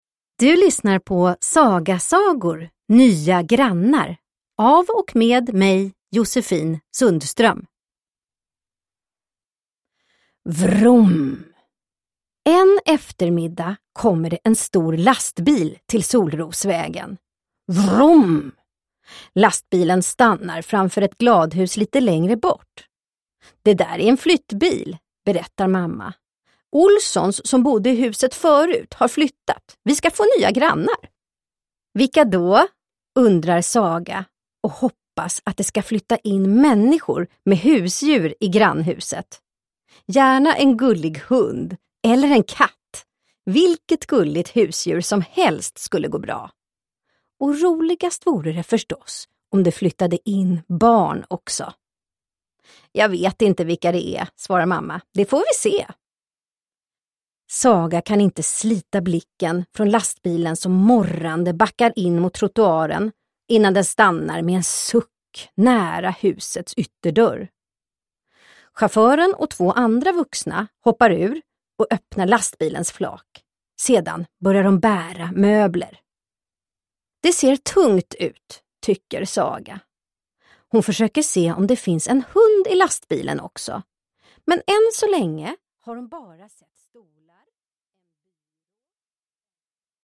Nya grannar – Ljudbok – Laddas ner